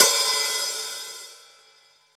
paiste hi hat8 open.wav